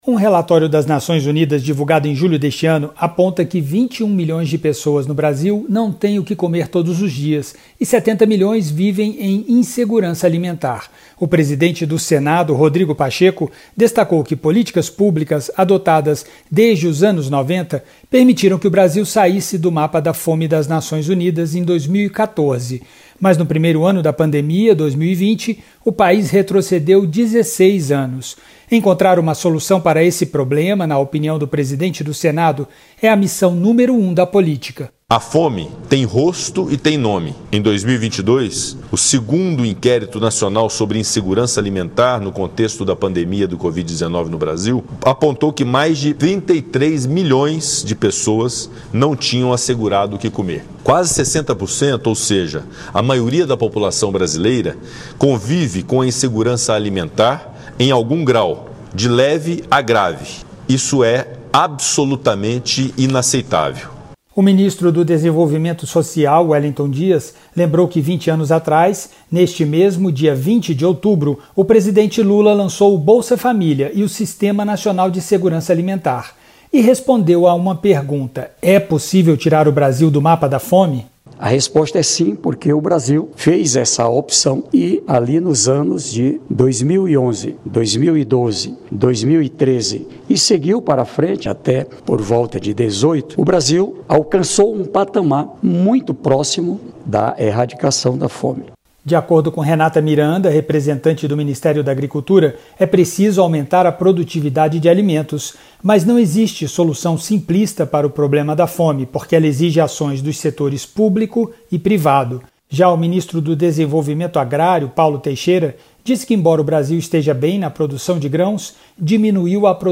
O Plenário do Senado discutiu nesta sexta-feira (20) ações de combate à fome no Brasil.
Sessão Temática